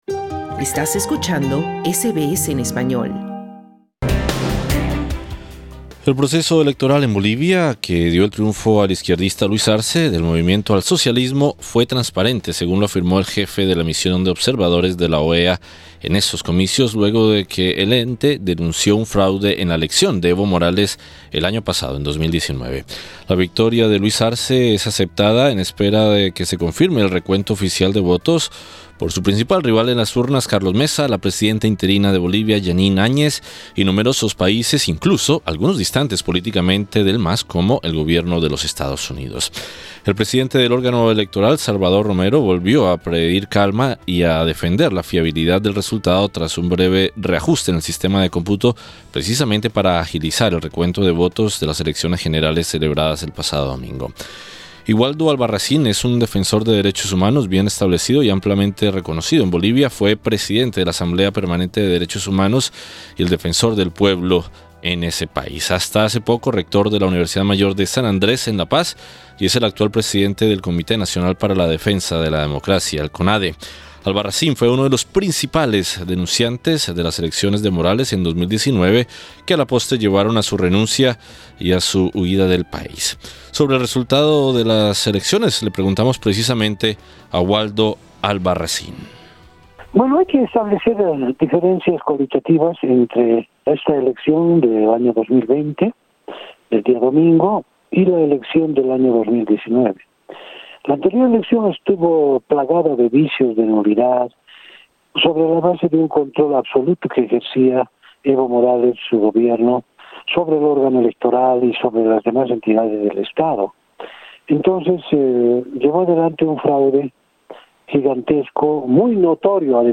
Sobre el resultado de las elecciones le preguntamos a Waldo Albarracín.